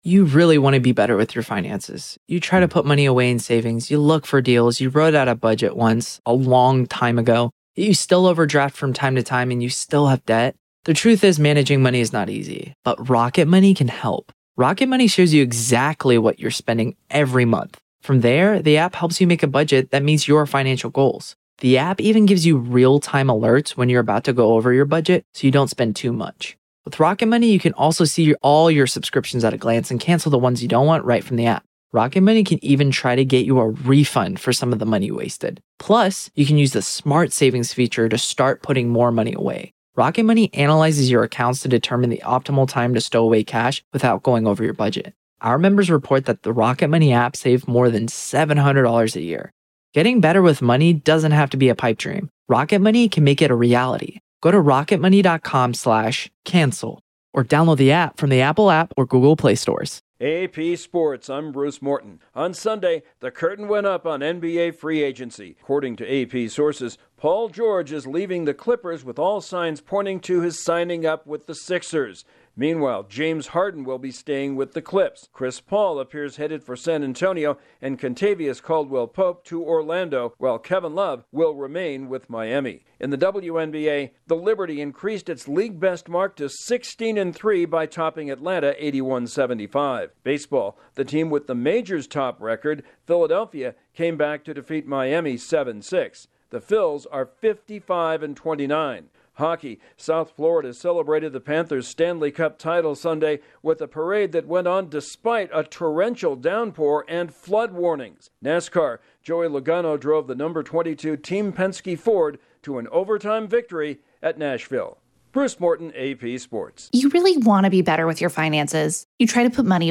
AP Sports SummaryBrief